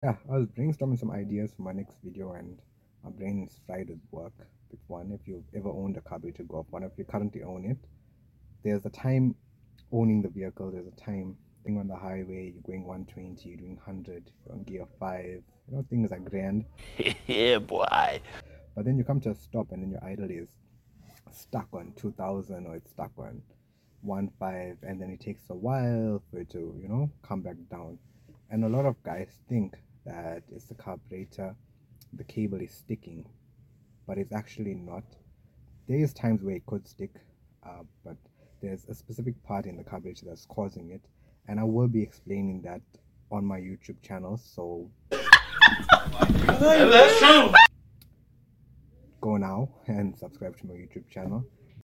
Next video update: Golf mk1 rev hanging on a carburetor